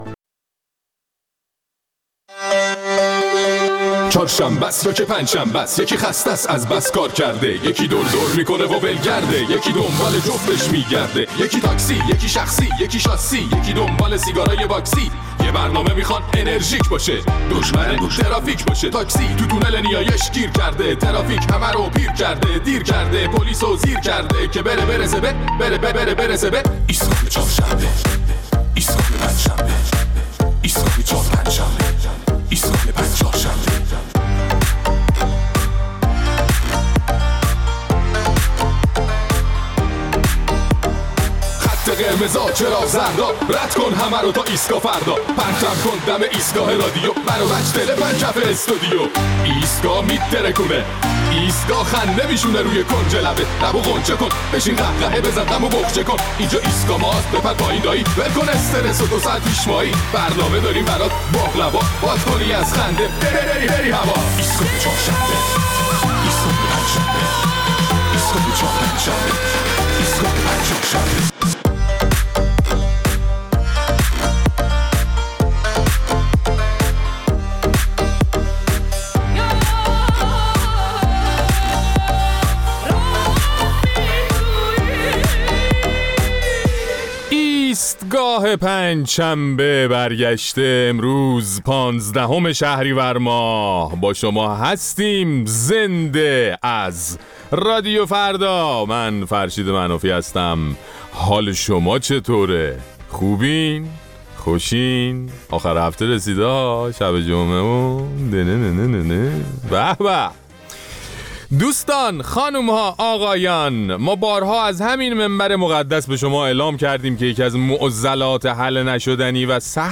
در این برنامه ادامه نظرات شنوندگان را در مورد صحبت‌های رهبر ایران درباره تاثیر خرابکاری دشمنان در کمبود پوشک در کشور می‌شنویم.